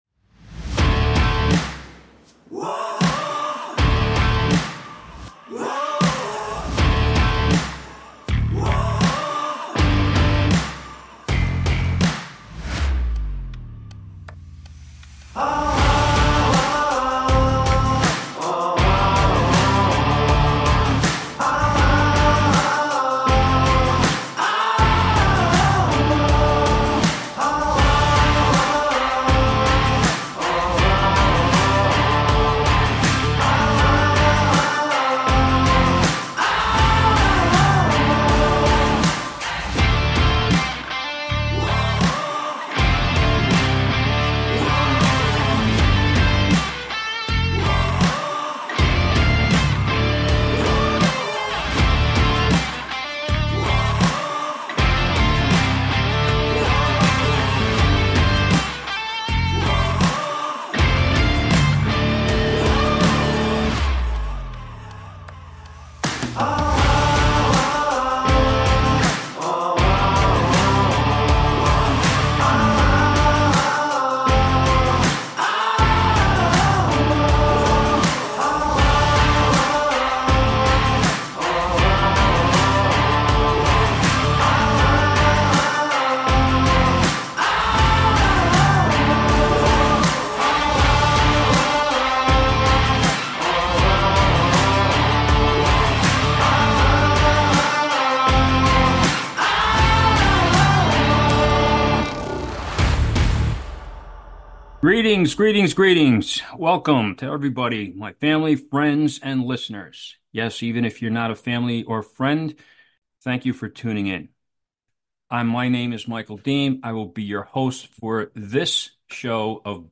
Subscribe Talk Show Bards, Rise!